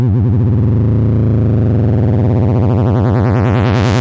It generates a simple sequence of electronic music out of raw wave forms based on hard coded instructions:
🔊 Rhythm